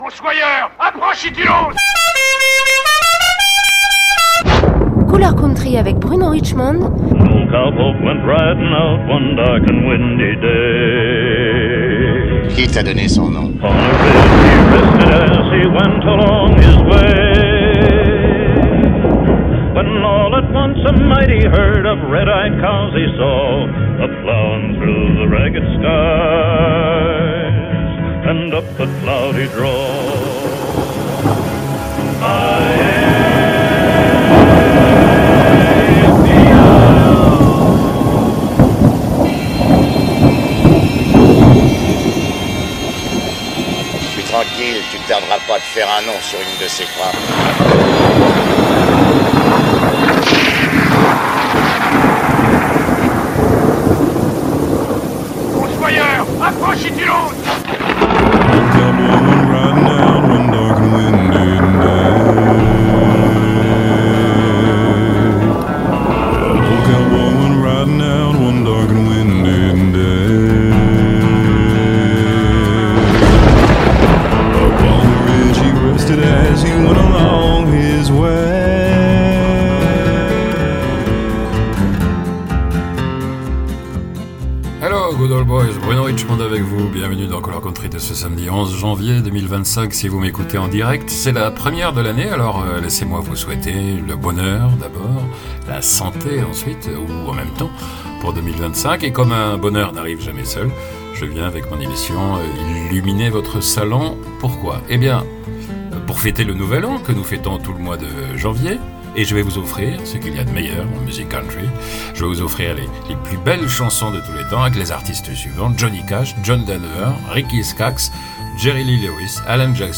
En ce temps de fête, puisque le Nouvel An se souhaite avec des étrennes tout le mois de janvier, voici mes cadeaux : parmi les plus belles chansons country (1960-1990).